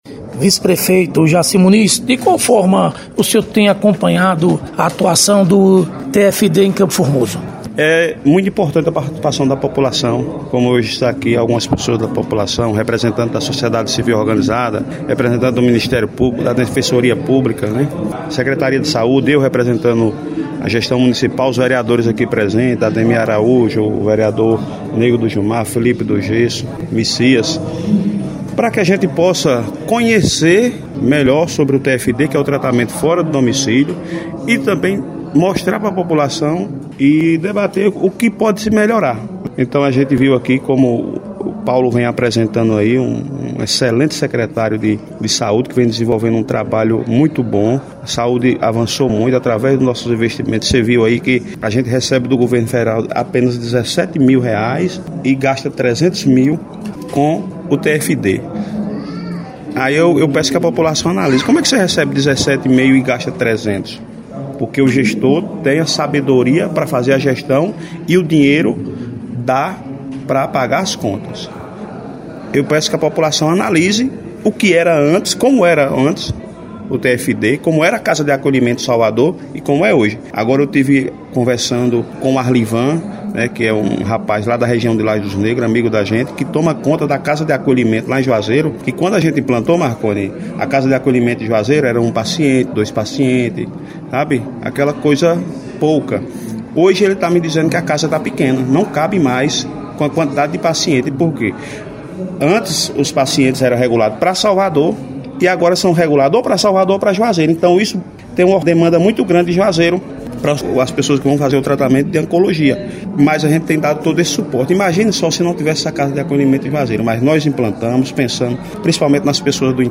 Vice-Prefeito de CFormoso, Jaci Muniz – Comenta sobre a atuação do TFD no município